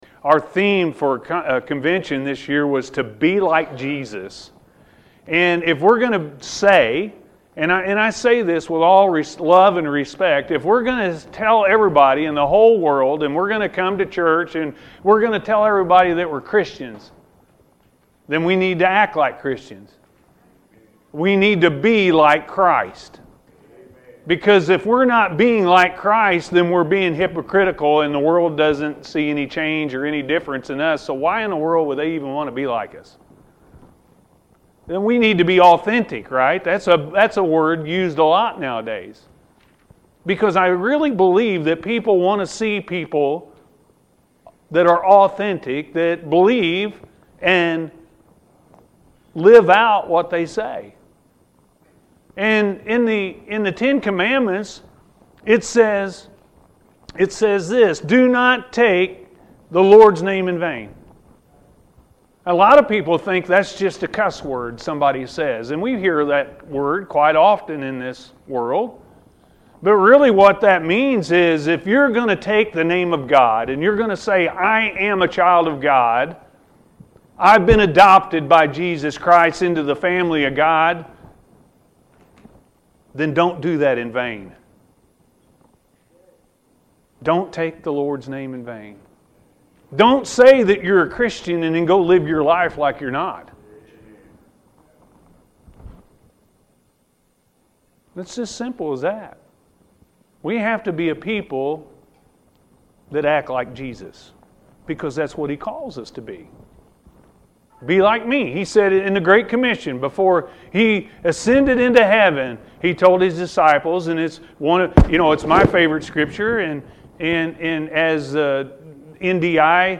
Jesus Looks At People With Love-A.M. Service